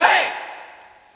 MZ Vox [African Hey!].wav